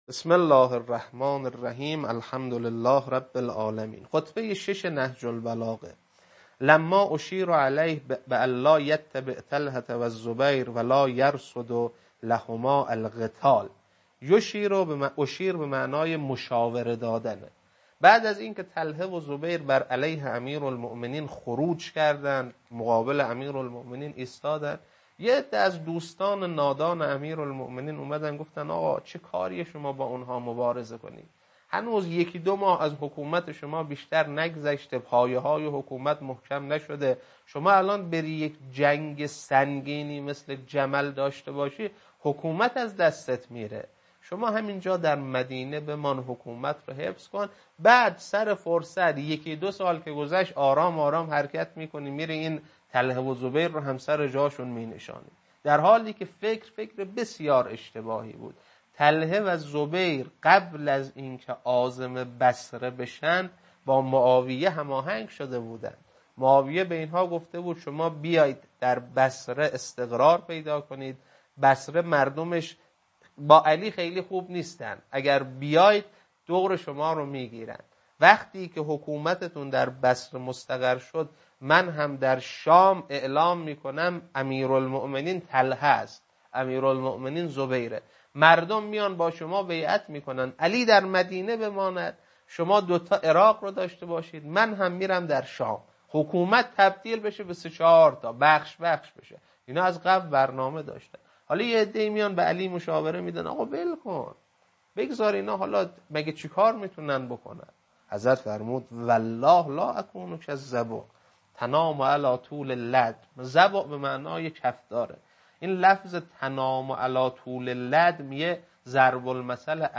خطبه 6.mp3
خطبه-6.mp3